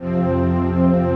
CHRDPAD102-LR.wav